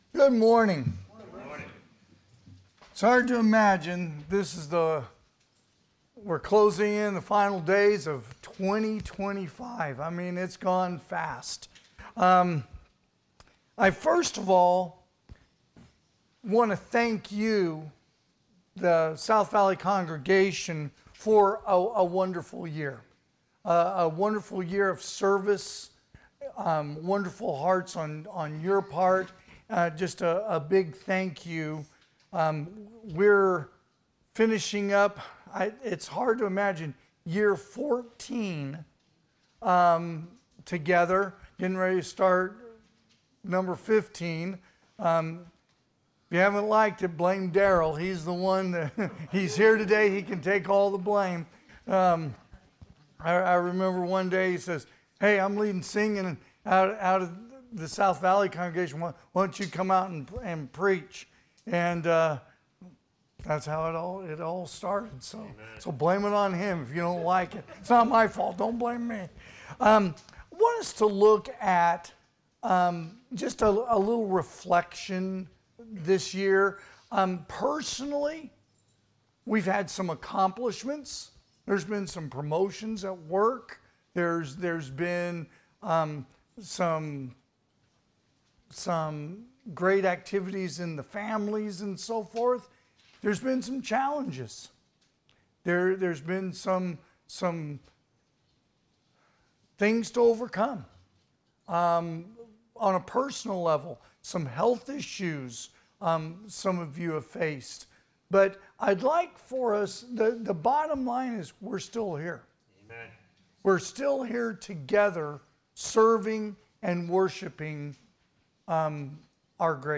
2025 (AM Worship) “2025”
Sermons